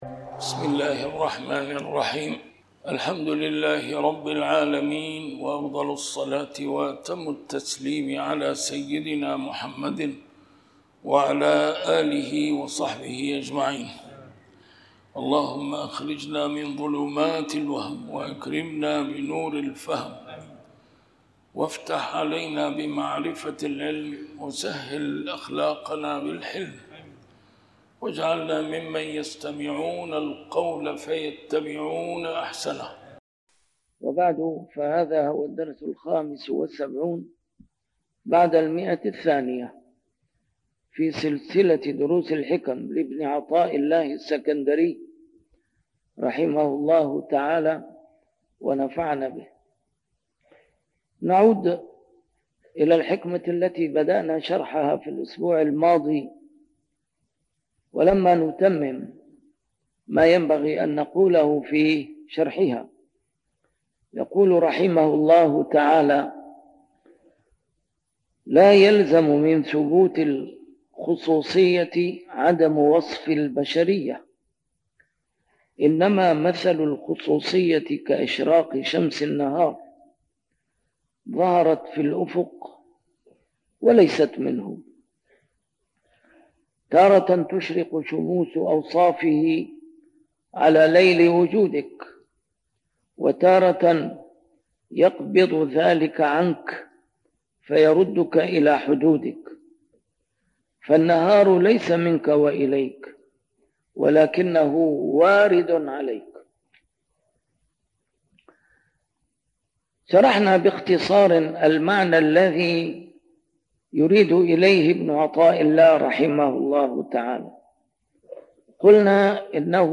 الدرس رقم 275 شرح الحكمة رقم 249 (تتمة)